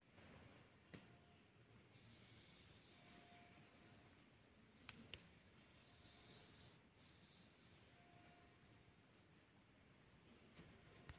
Corpo, archetto da viola.
Performance, 2023.
corda-su-pelle-01.mp3